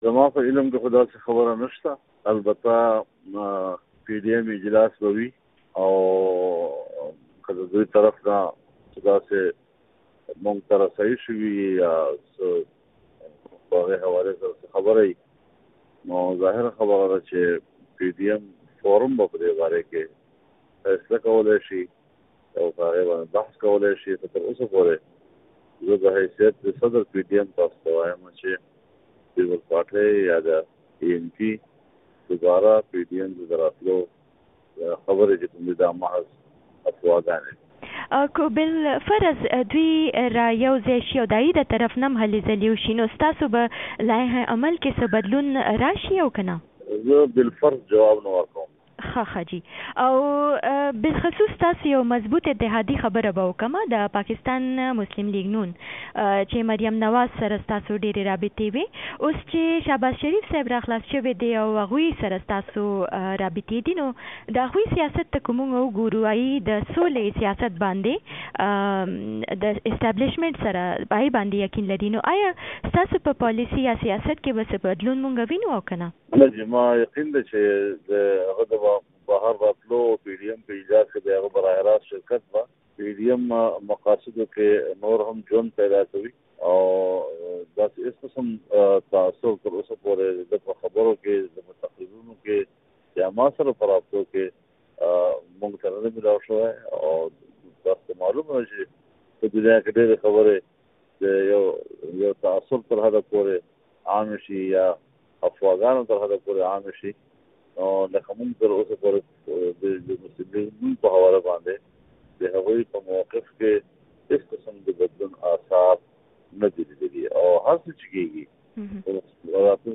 فضل الرحمان د مې پر ۱۹مه له مشال راډیو سره په ځانګړې مرکه د پاکستان پيپلز ګوند او عوامي نيشنل ګوند په پي ډي ايم کې د بیا شاملېدو خبرونه افواګانې (اوازې) وبللې